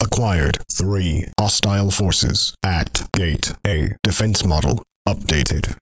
For the sake of clarity, the audio files below do not use the glitch or background noises.
Announcement when the Mini Wave spawns::